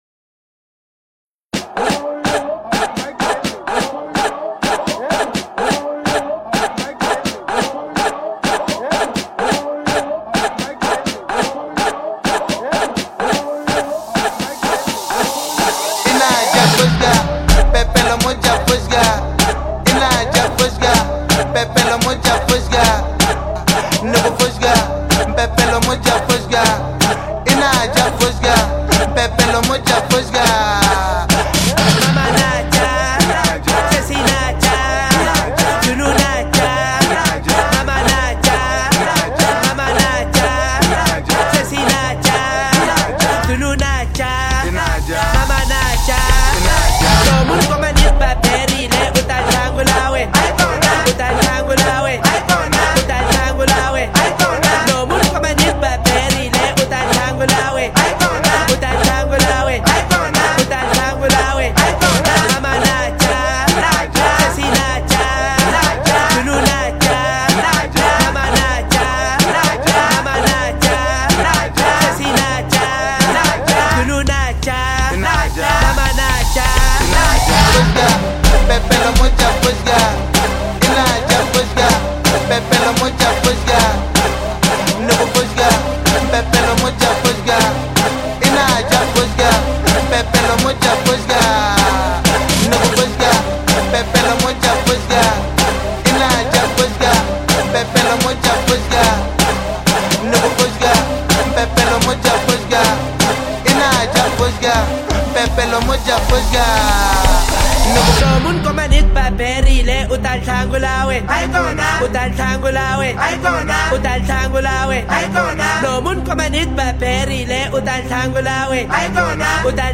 Can the Gqom remix become a smash?